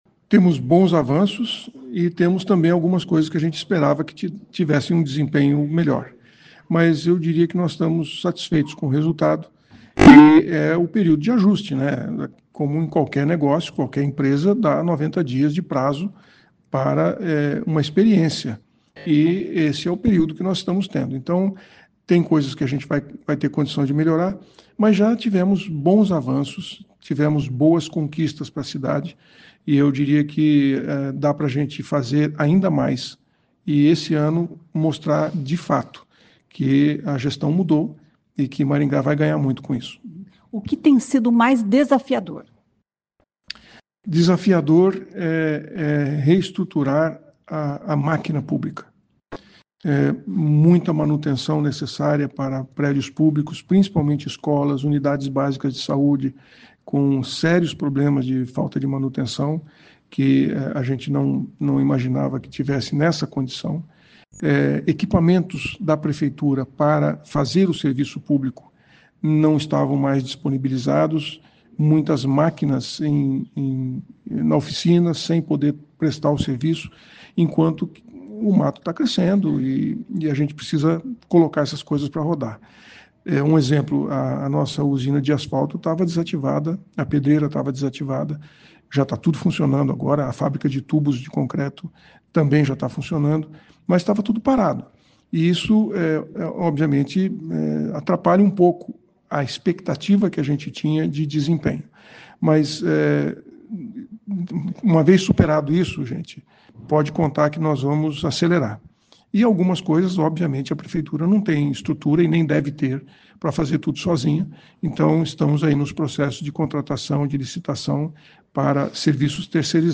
Em entrevistas a repórteres, o prefeito falou sobre a zeladoria urbana, um dos serviços mais desafiadores da gestão, que completa nesta terça-feira (10), 100 dias.